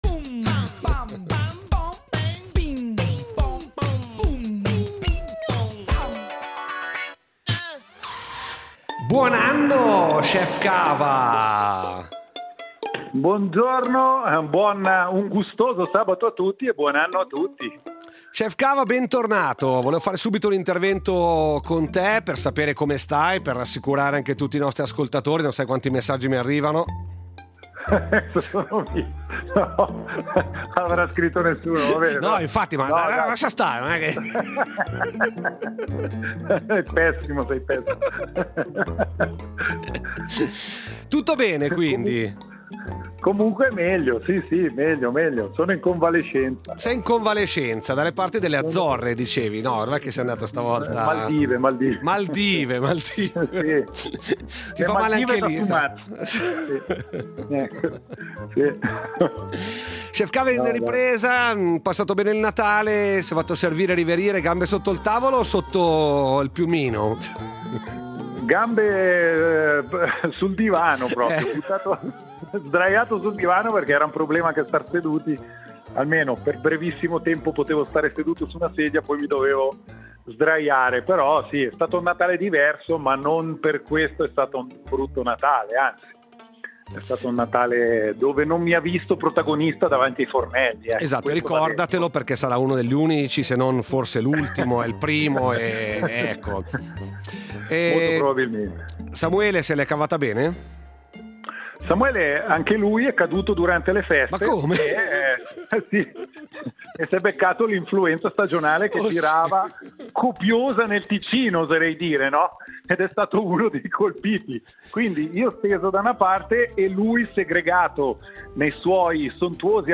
Il quiz radiofonico con in palio un grembiule loggato Rete Tre